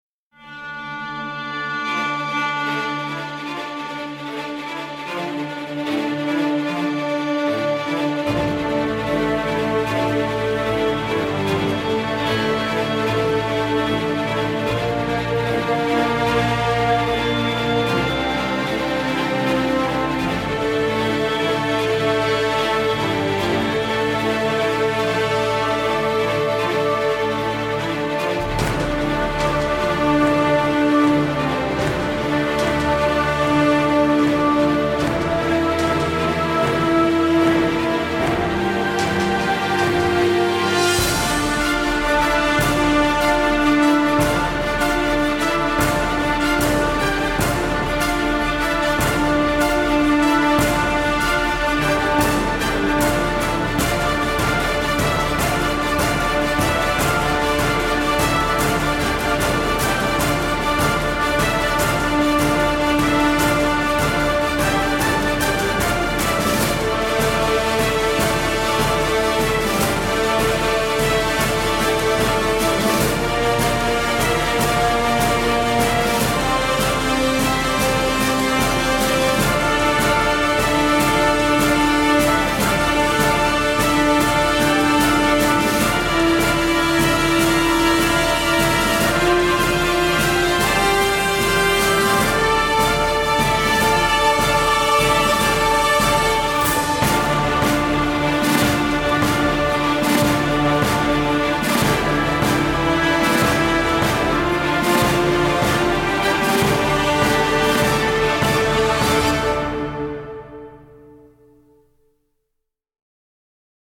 Epic orchestral track for trailers.
Epic orchestral track for trailers and battle.